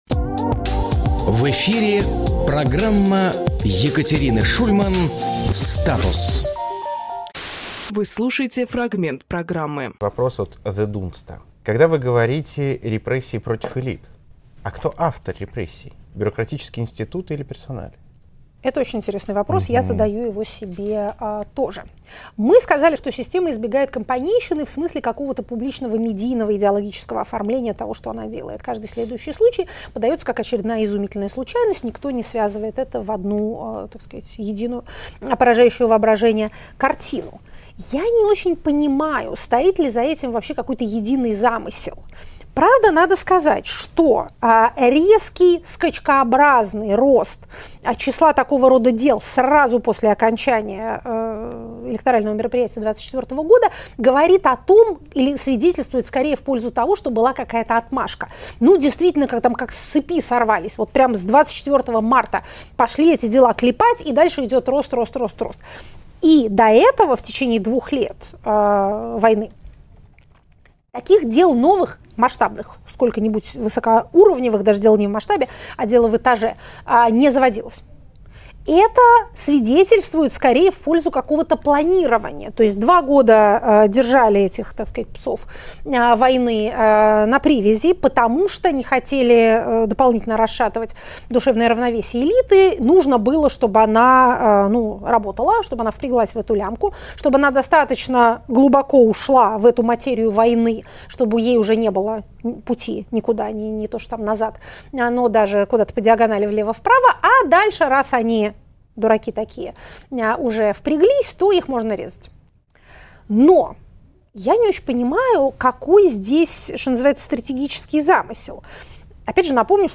Екатерина Шульманполитолог
Фрагмент эфира от 15.07.25